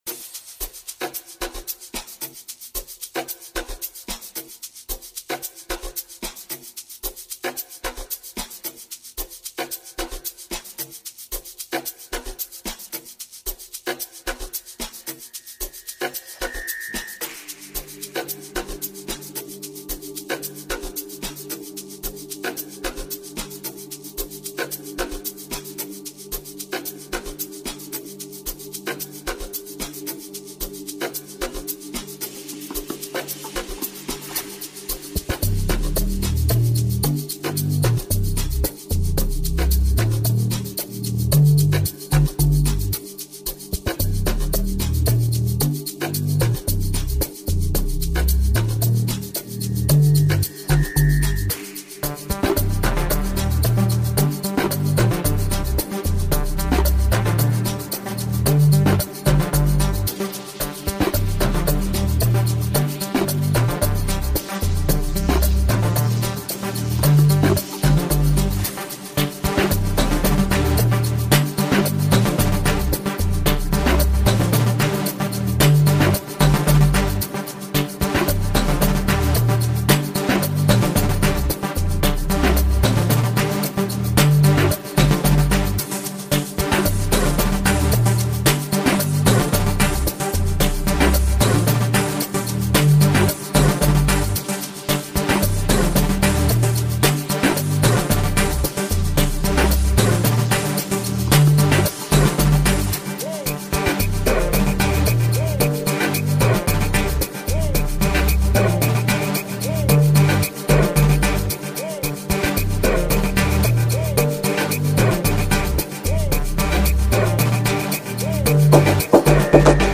Sgija